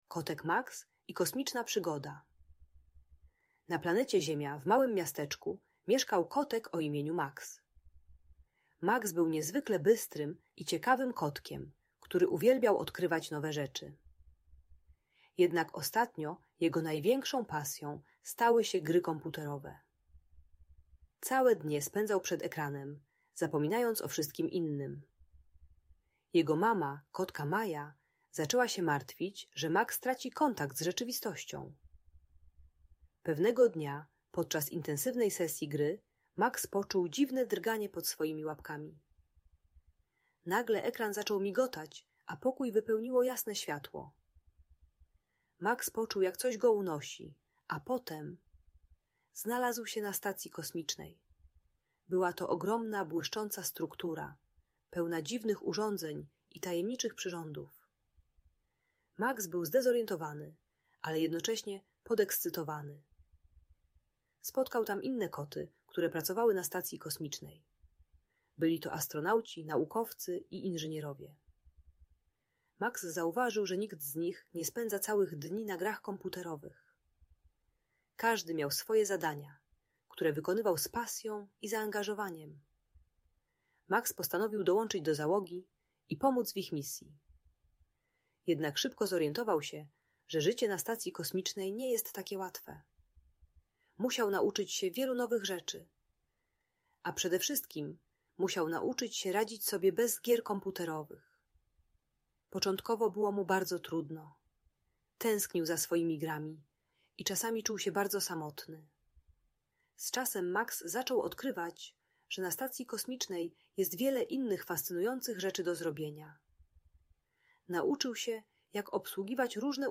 Kotek Max i Kosmiczna Przygoda - Bajki Elektronika | Audiobajka